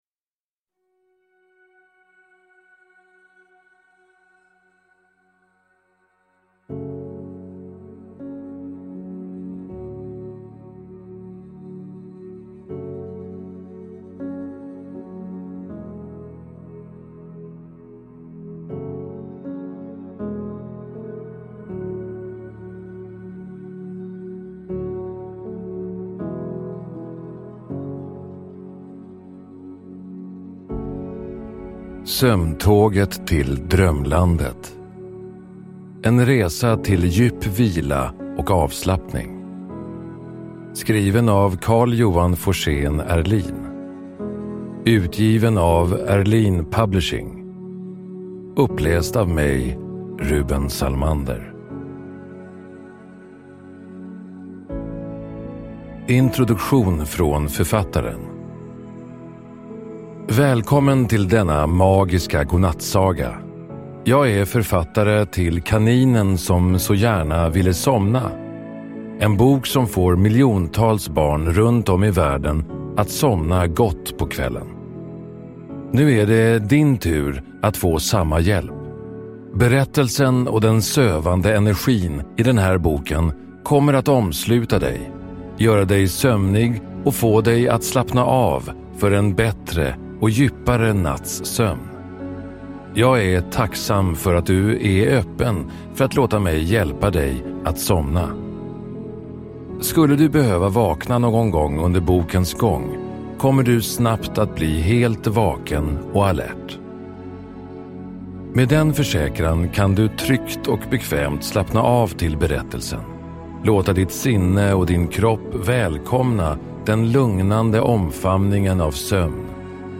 Ljudboken är skapad med specialkomponerad sömnmusik, binaural beats och en avslutande jojk som skapar en tidlös och spirituell känsla av lugn.
Uppläsare: Rueben Sallmander